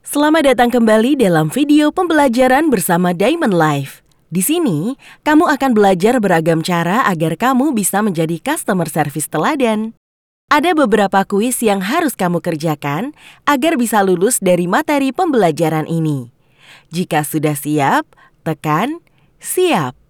Commercial, Deep, Mature, Warm, Corporate
Explainer